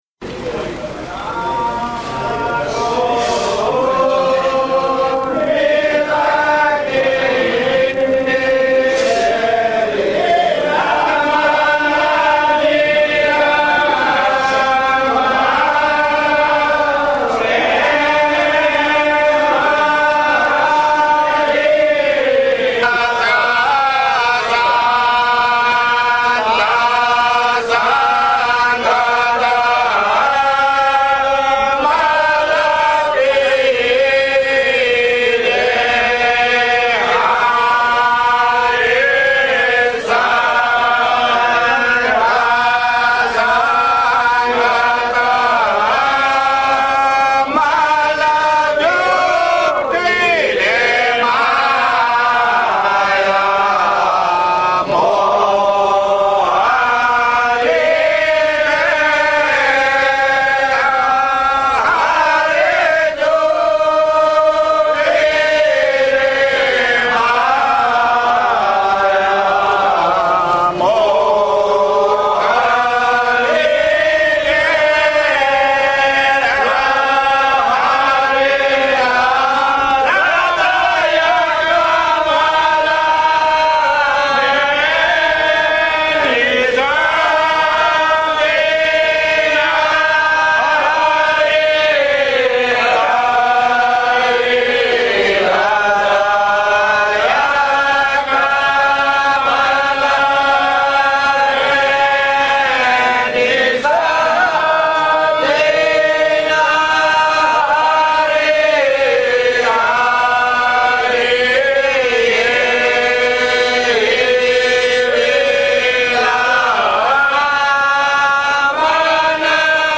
Kirtan - Syadla (1997)